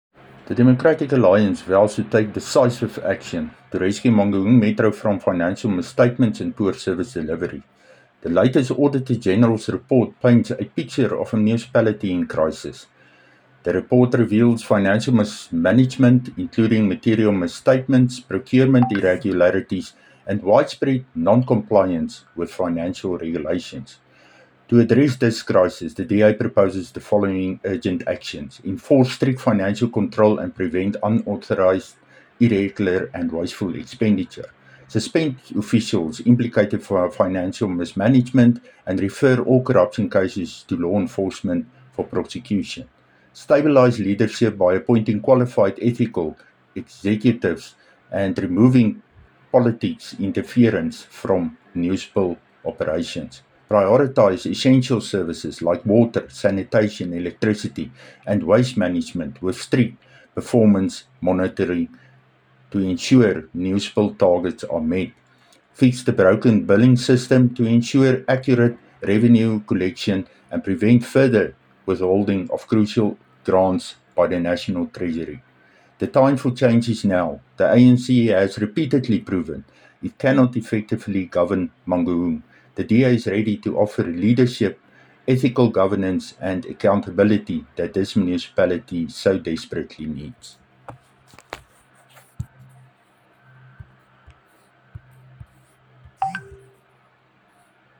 Afrikaans soundbites by Cllr Dirk Kotze and